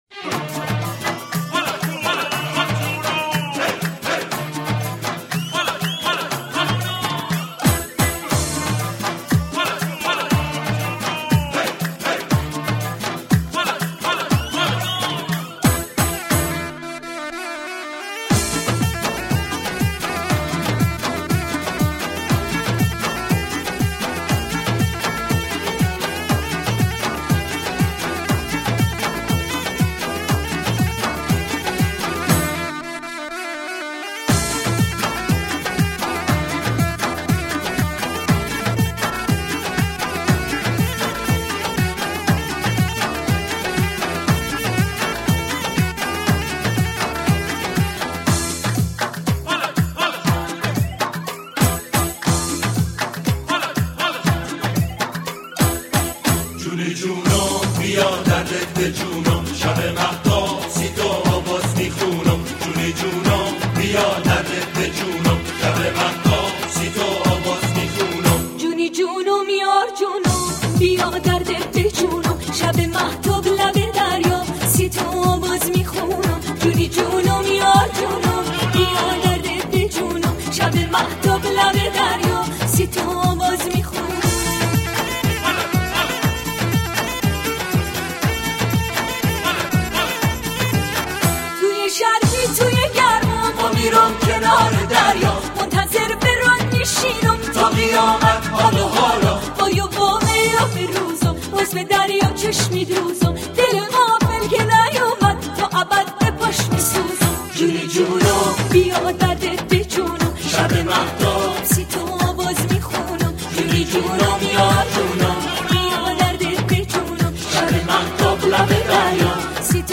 دانلود ریمیکس این آهنگ کیفیت عالی